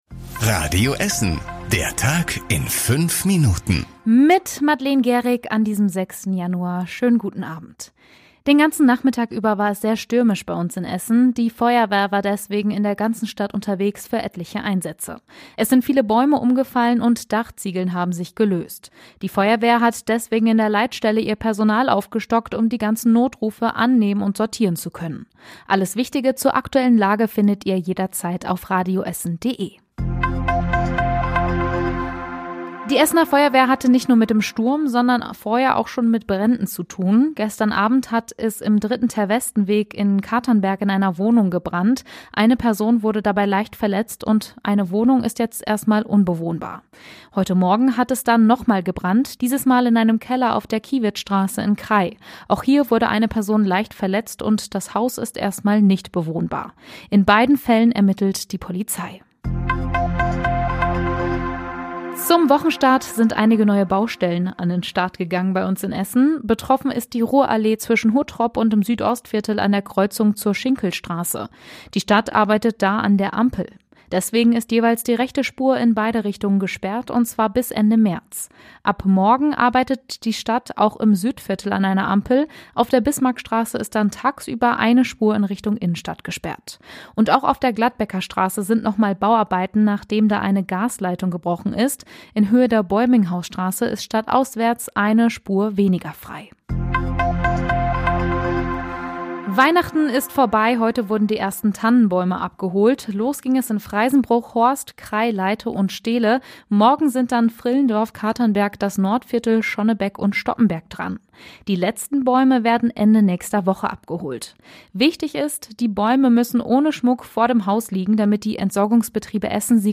Unsere Nachrichtenredakteure fassen den Tag für Euch noch mal zusammen.
Täglich um 19.30 bei uns im Radio.